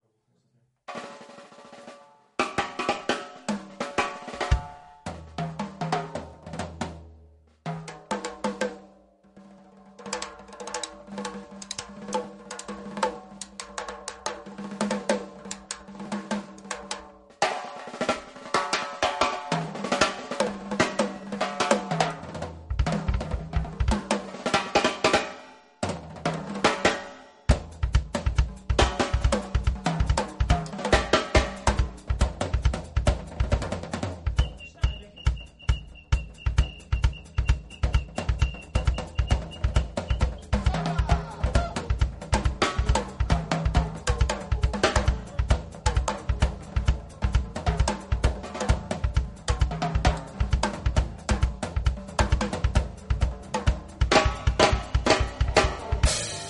Descarga de Sonidos mp3 Gratis: bateria 1.
bajo_32.mp3